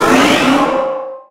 Cri de Méga-Ectoplasma dans Pokémon HOME.